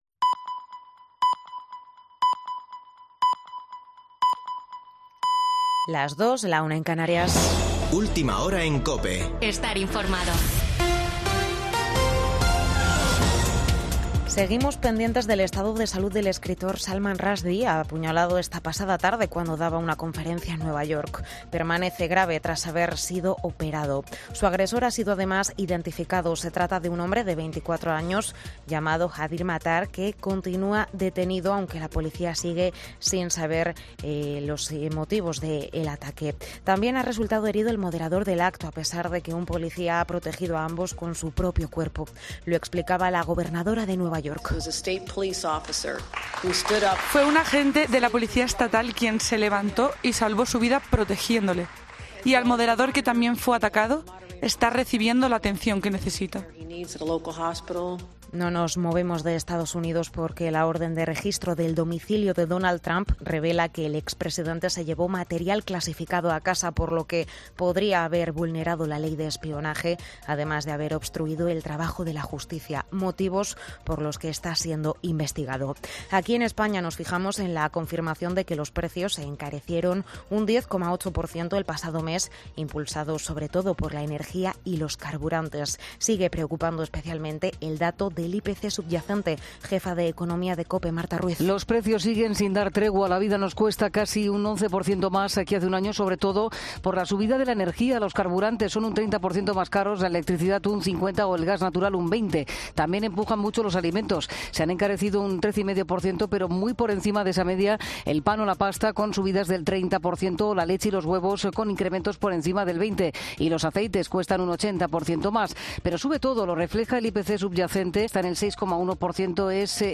Boletín de noticias de COPE del 13 de agosto de 2022 a las 02.00 horas